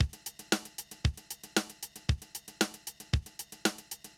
MOO Beat - Mix 6.wav